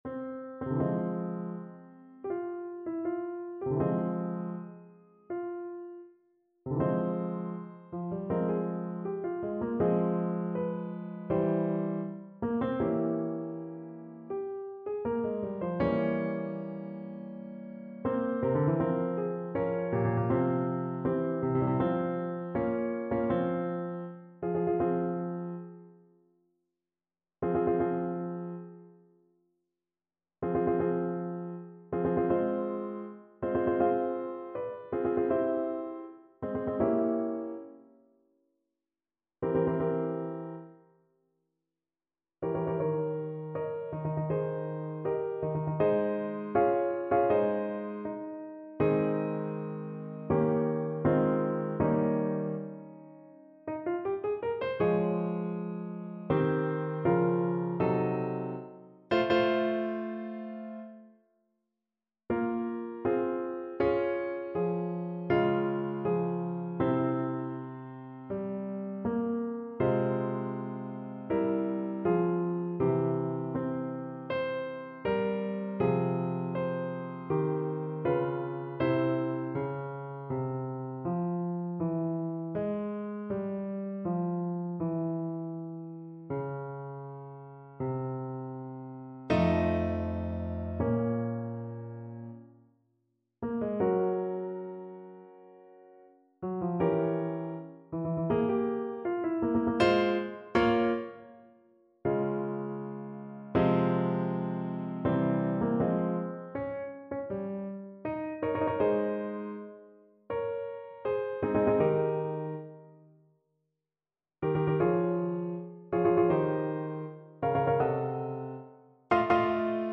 French Horn
Ab major (Sounding Pitch) Eb major (French Horn in F) (View more Ab major Music for French Horn )
2/4 (View more 2/4 Music)
Adagio assai ( = 80)
Classical (View more Classical French Horn Music)